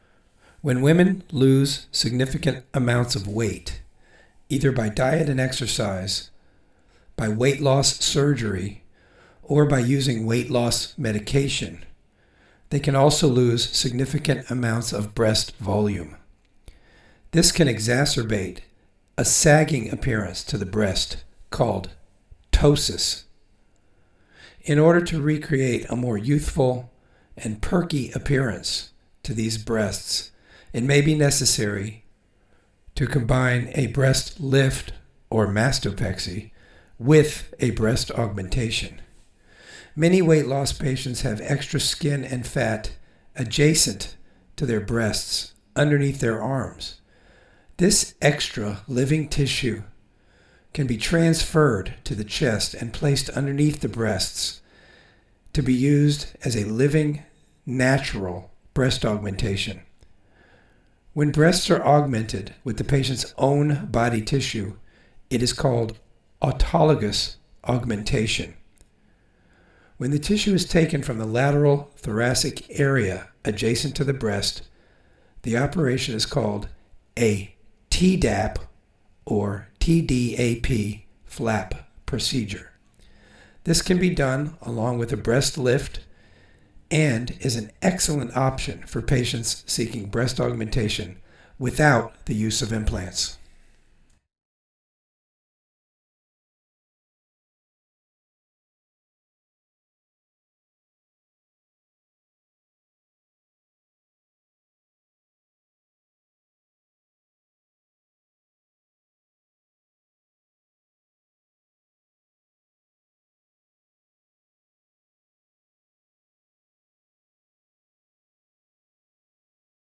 Article Narration